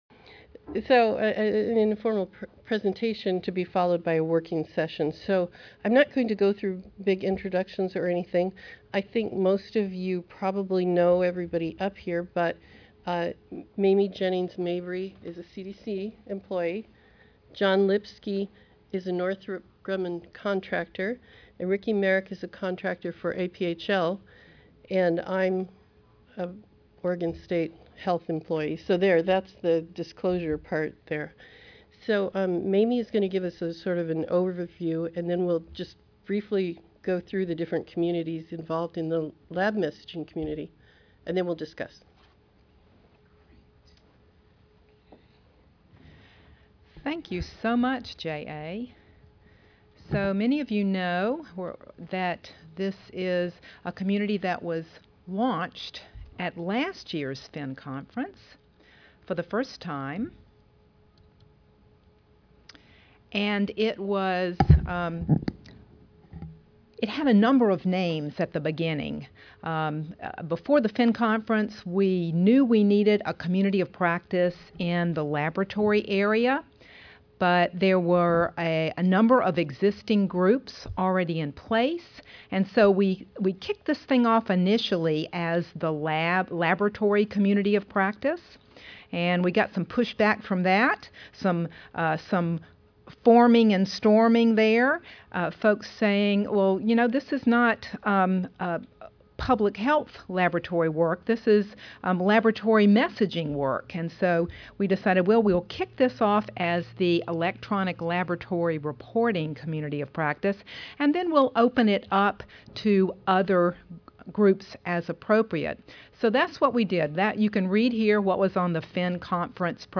This community working session for the emergent Laboratory Messaging Community of Practice (LMCoP) will begin with some context for the formation of this unique CoP provided by a representative of the closely-related Vocabulary and Messaging CoP, followed by a panel review of the objectives and scope of the three working groups, and finally, a facilitated working session during which the CoP will continue its work to